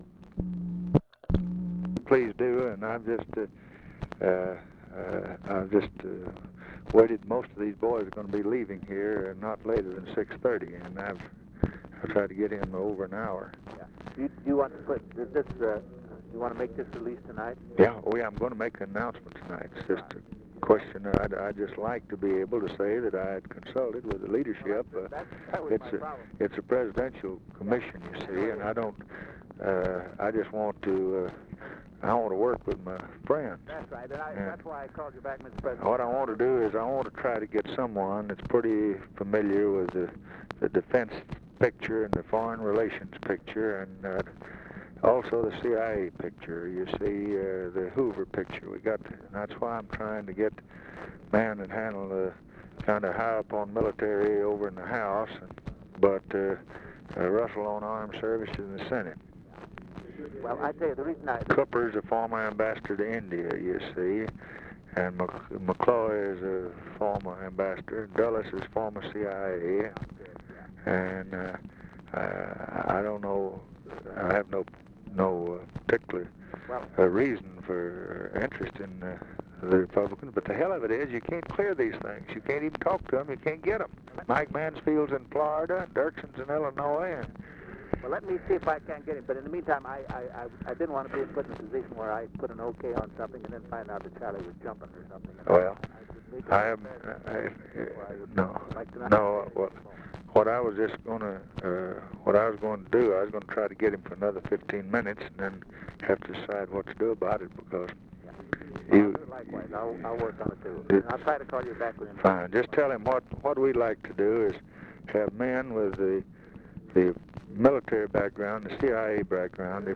Conversation with LES ARENDS, November 29, 1963
Secret White House Tapes